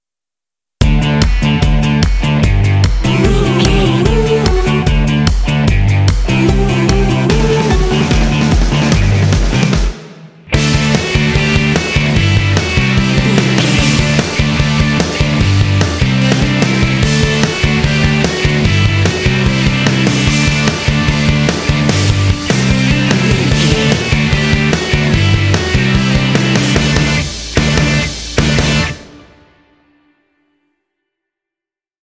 This cute indie track is ideal for action and sports games.
Uplifting
Ionian/Major
Fast
energetic
cheerful/happy
electric guitar
bass guitar
drums
vocals
classic rock
alternative rock